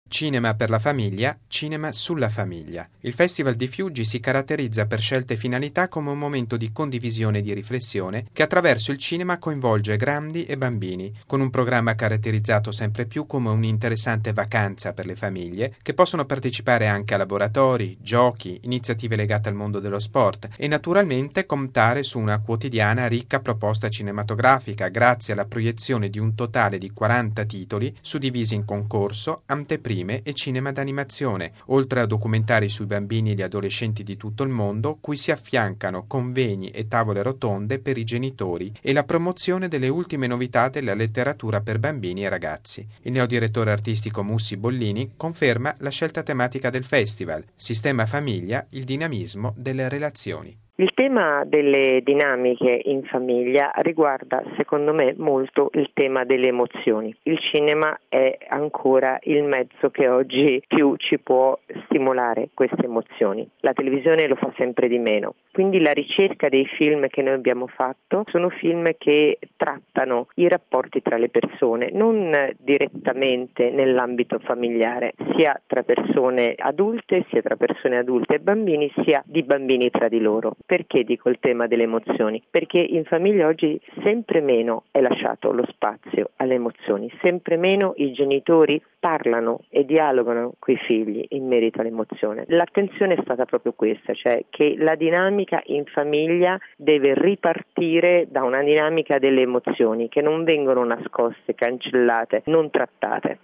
La quarta edizione del Fiuggi Family Festival, che si apre oggi, 28 luglio, nella città laziale ed è in programma fino al 31, si caratterizza anche quest’anno per un’offerta di film e iniziative legate al mondo della famiglia, con momenti di riflessione, stimolati dal cinema, per rieducare alle emozioni e al dinamismo delle relazioni tra genitori e figli. Il servizio